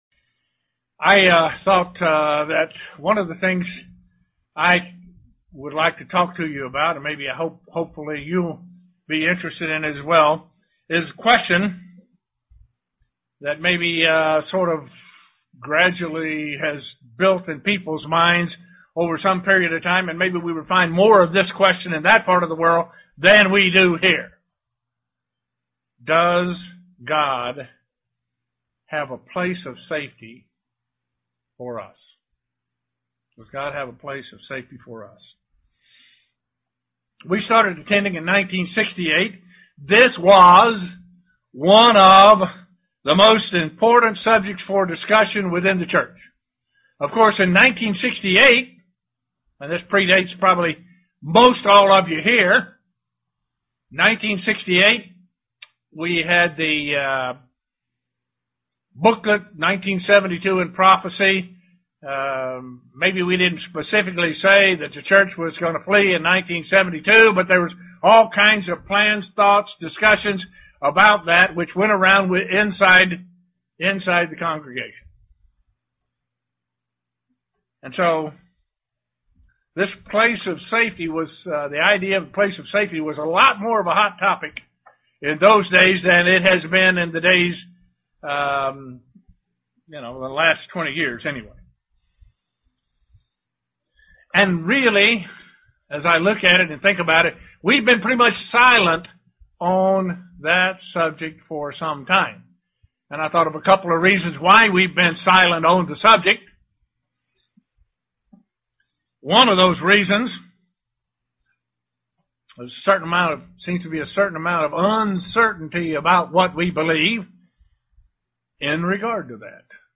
Given in Elmira, NY
Print Is there a place of safety addressed in the Bible UCG Sermon Studying the bible?